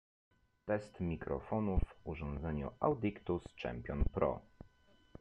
Mowa o umieszczeniu mikrofonu… na kablu.
…oraz wpięty w gniazdo minijack przewód od Marshall Monitor + przejściówka 3,5 mm na USB-C marki Baseus.